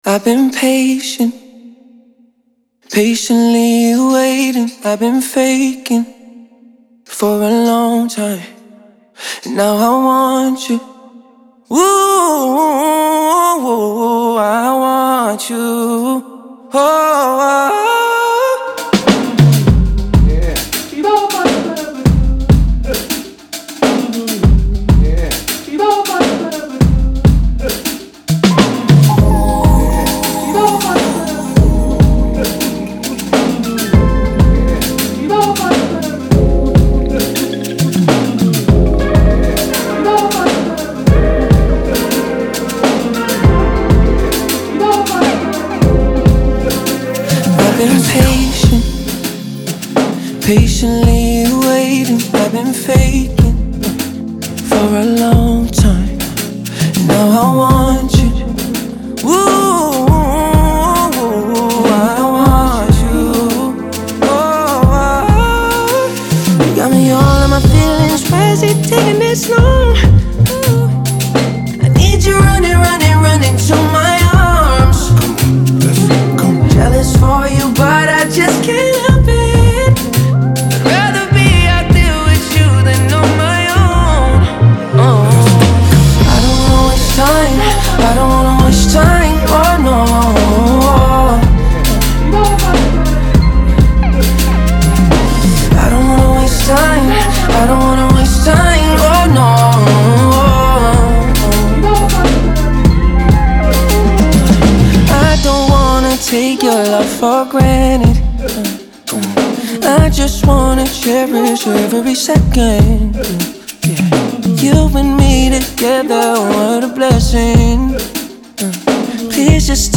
Genre : Soul, Funk, R&B